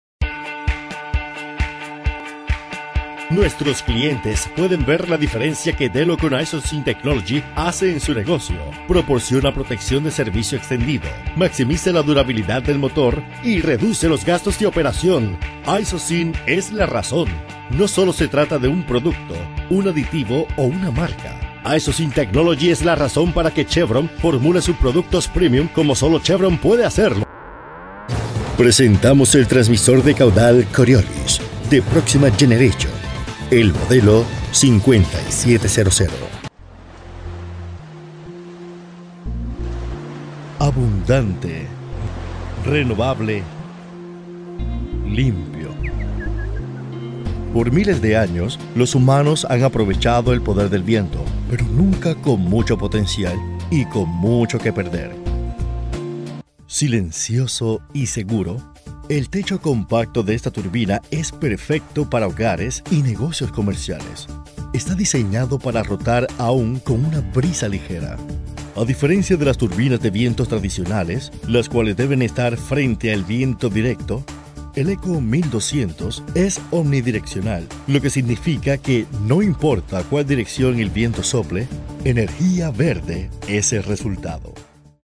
Adult (30-50), Older Sound (50+)
Warm and deep native Spanish voice ideal for narrations, sexy and fun for commercials, smooth and professional for presentations.
All our voice actors have professional broadcast quality recording studios.
Male Voice Over Talent